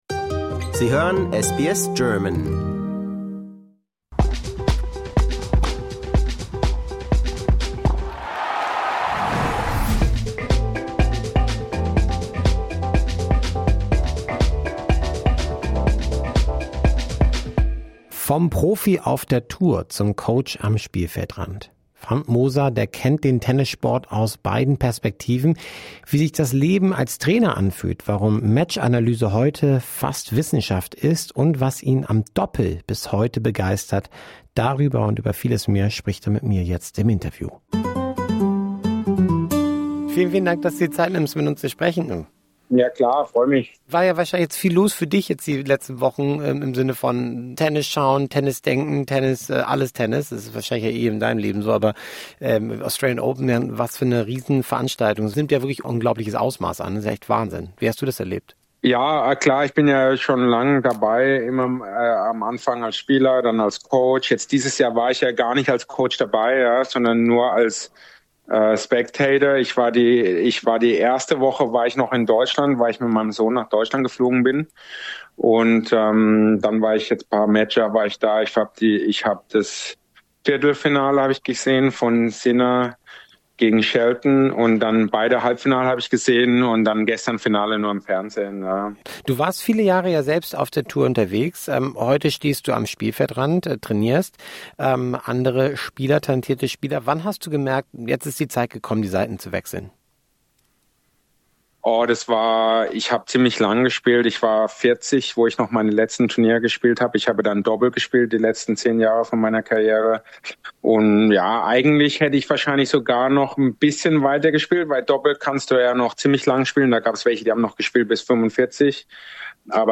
Im Interview spricht er über den Perspektivwechsel vom Court zur Coaching-Box, moderne Matchanalyse, Trainingsmethoden und warum das Doppel für ihn bis heute eine besondere Faszination hat.